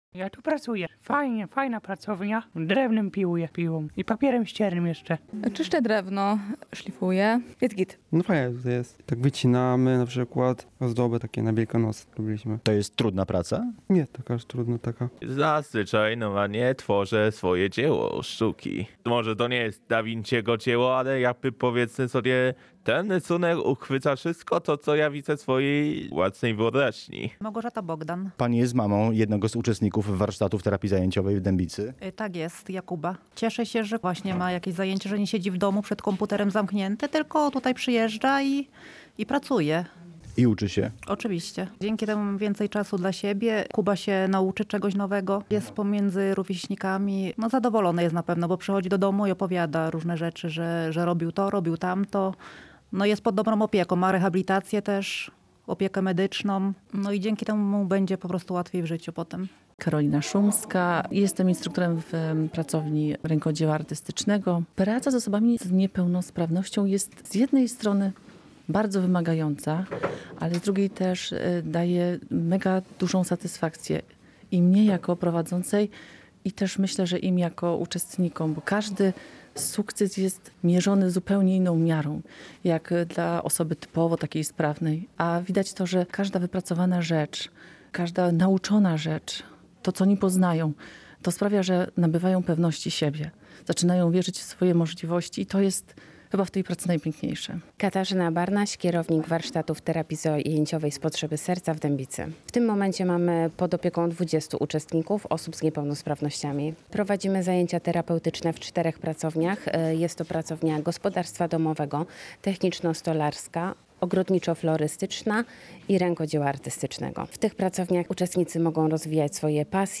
Ruszył Warsztat Terapii Zajęciowej • Relacje reporterskie • Polskie Radio Rzeszów